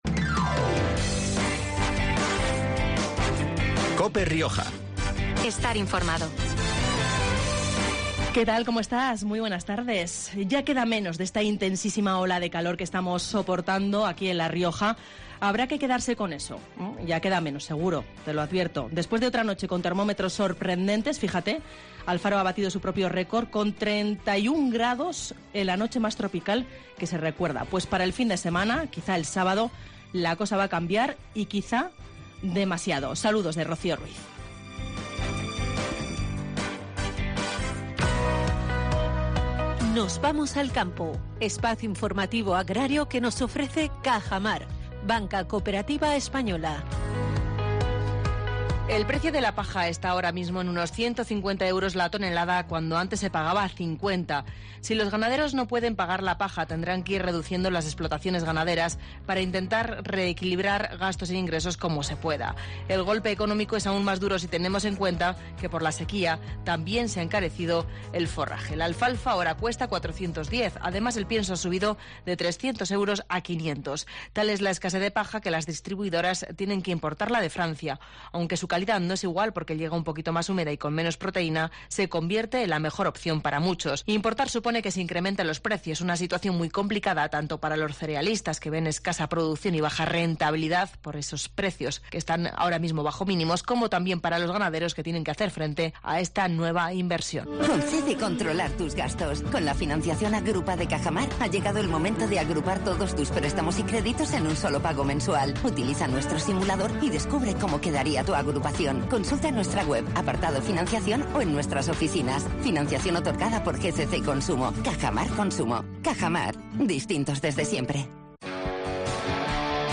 Hoy han pasado por los micrófonos de COPE para hablarnos, sobr etodo, de la esencia tan especial de este torneo de tenis.